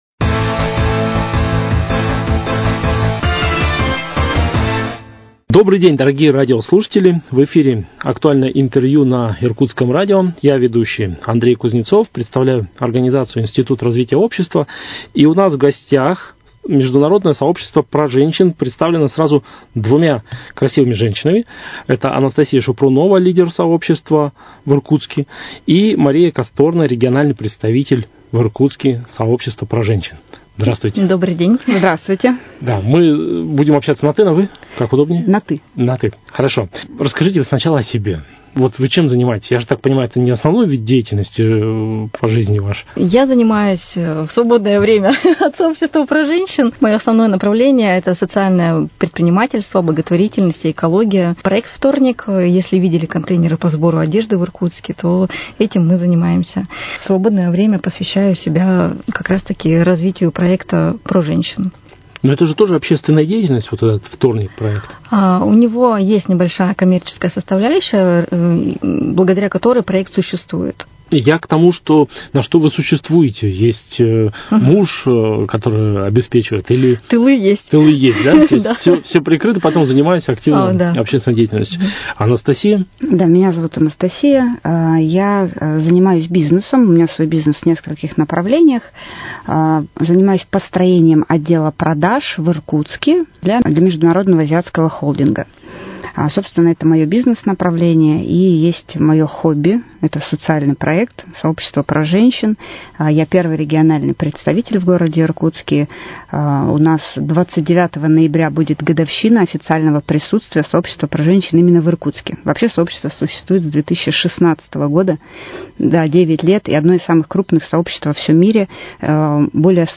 Актуальное интервью: Беседа с лидерами международного сообщества “PROженщин”